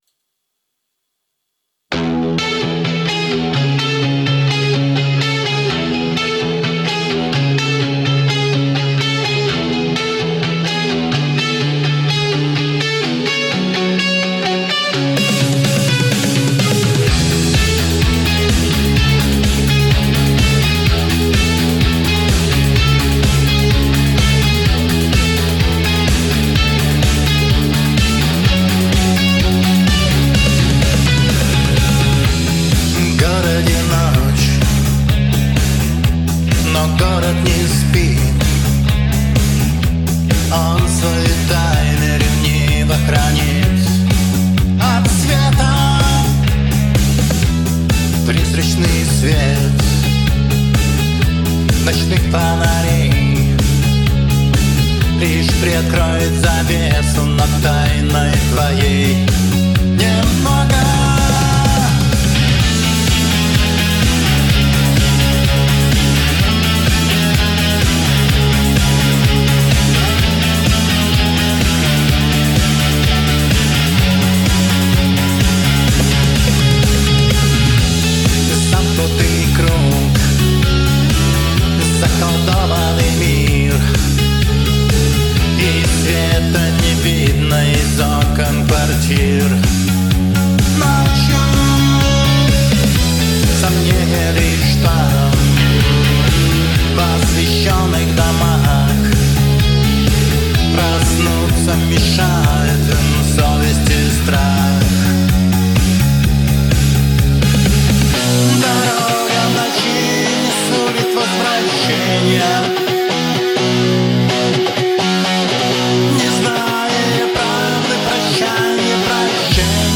Ai Generated
• Жанр: Рок